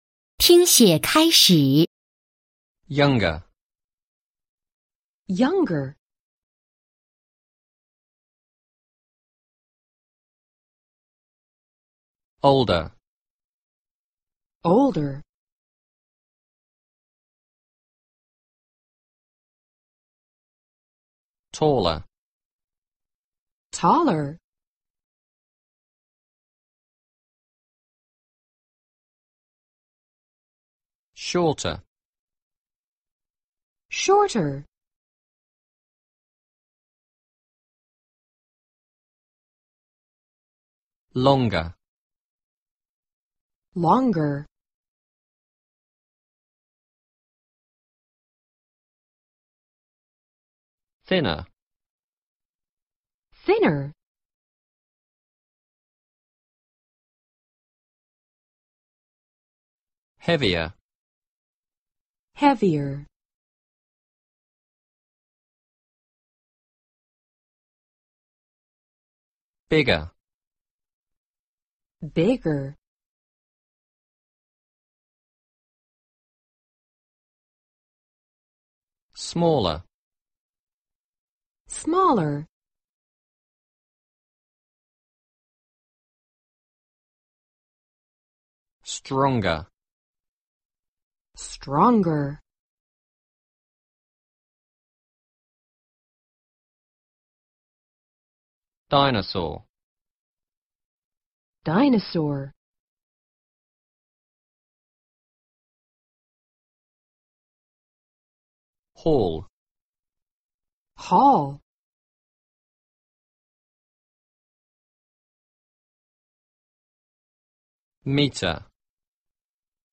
单词听写